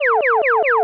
Zapps_03.wav